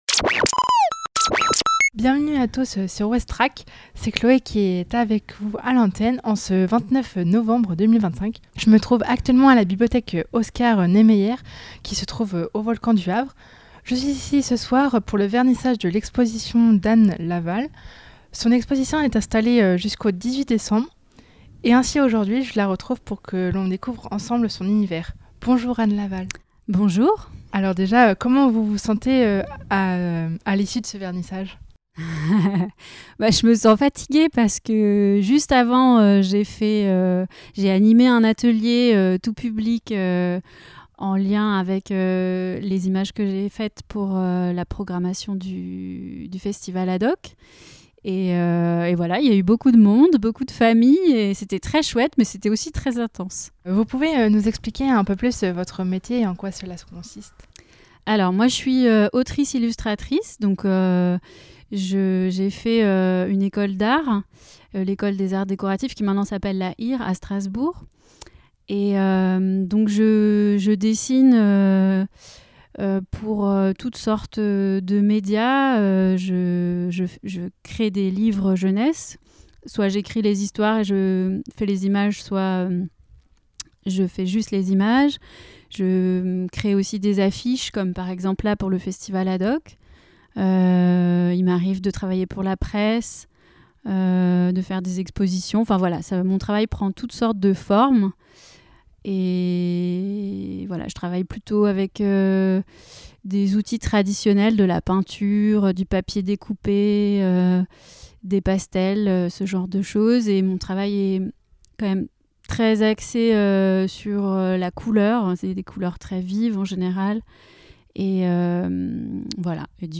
lors du vernissage de l'exposition.